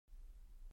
Aудиокнига Поздний ужин